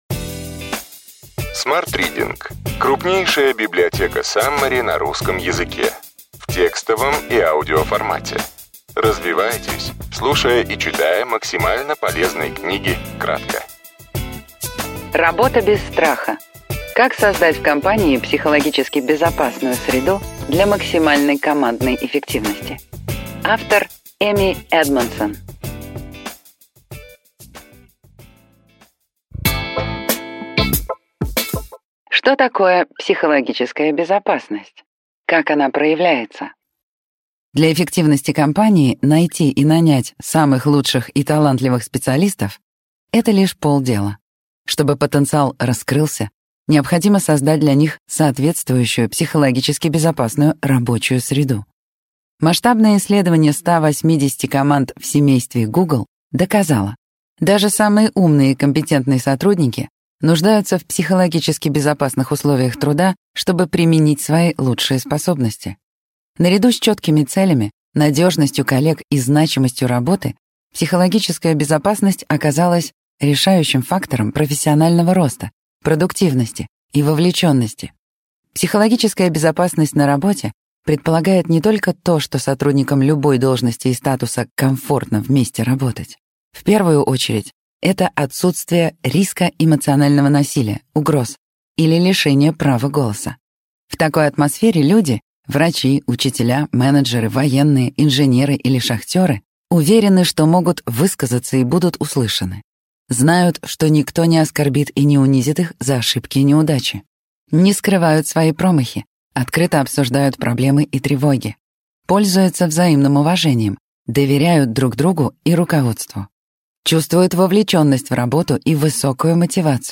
Аудиокнига Ключевые идеи книги: Работа без страха.